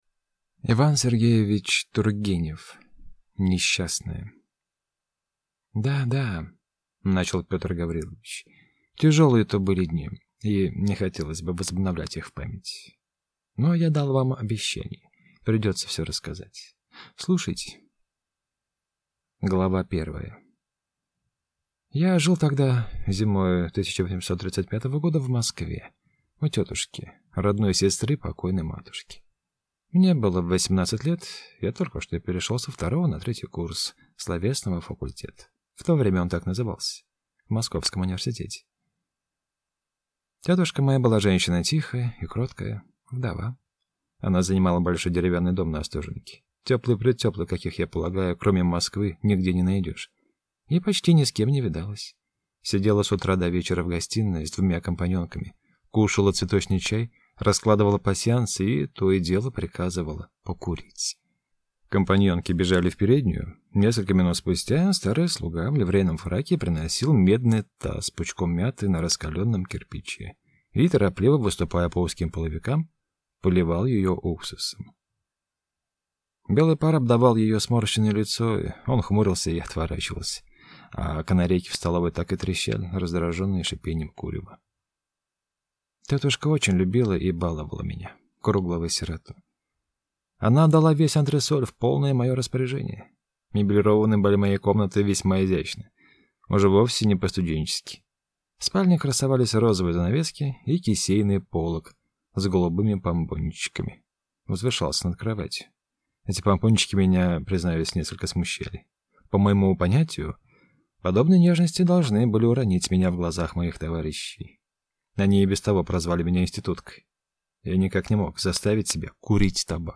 Аудиокнига Несчастная | Библиотека аудиокниг